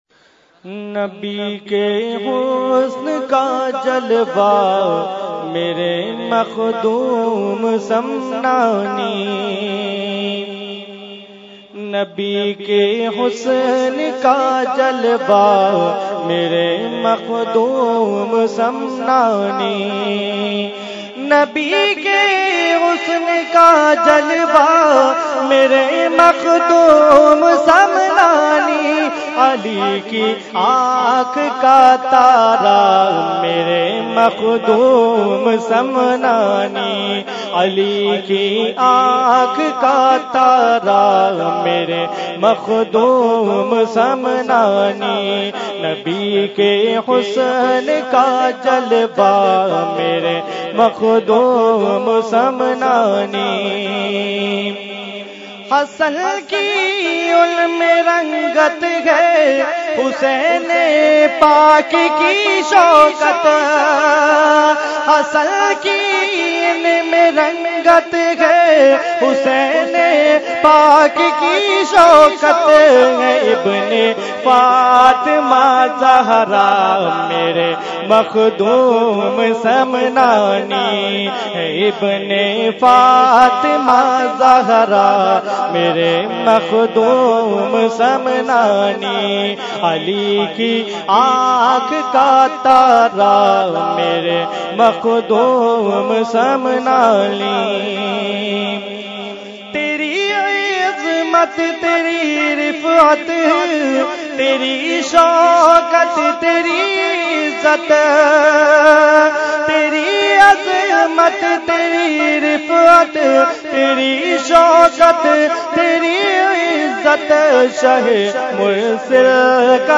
Weekly Tarbiyati Nashist held on 3/1/2016 at Dargah Alia Ashrafia Ashrafabad Firdous Colony Karachi.
Category : Manqabat | Language : UrduEvent : Urs Makhdoome Samnani 2017